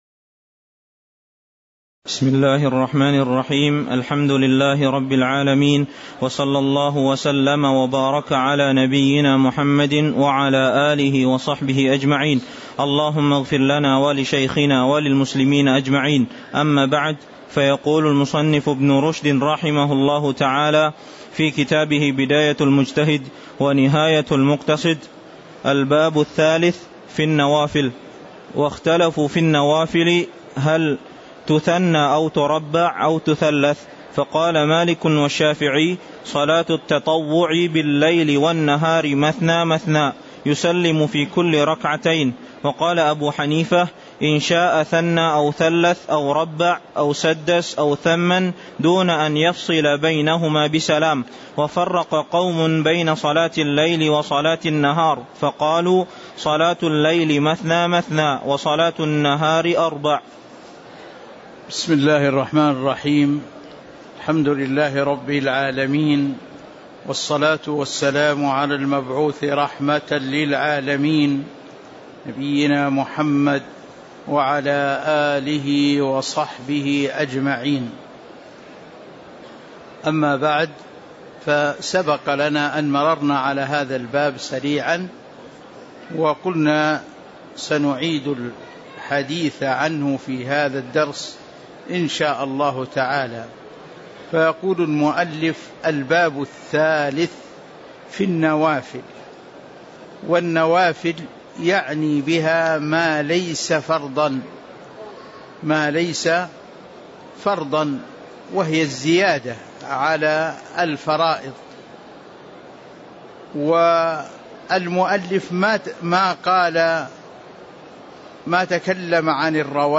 تاريخ النشر ١٥ جمادى الآخرة ١٤٤٤ هـ المكان: المسجد النبوي الشيخ